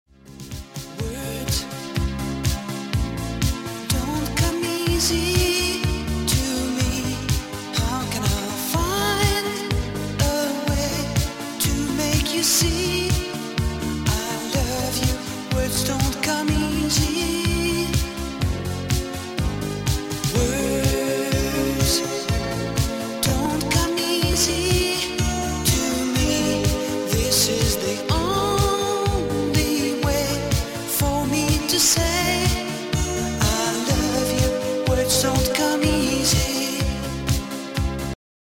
• Качество: 128, Stereo
диско
80-е
танцы